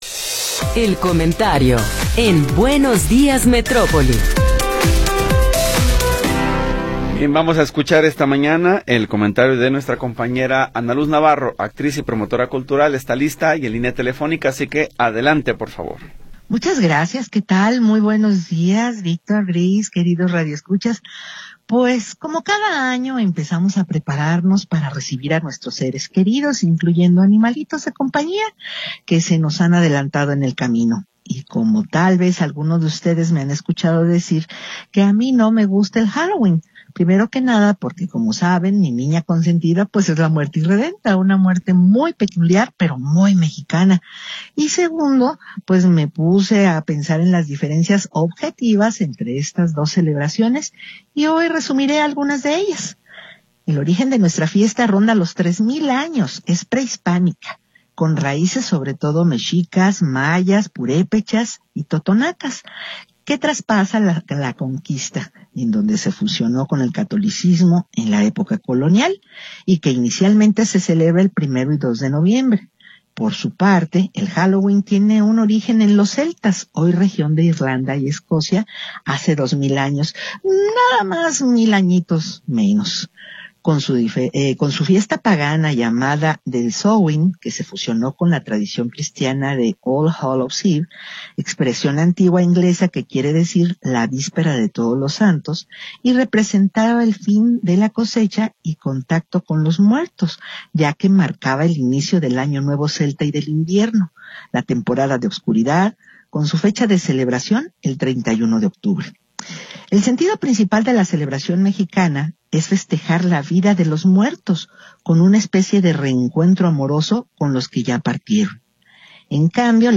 promotora cultural, actriz de teatro y comunicadora
Comentario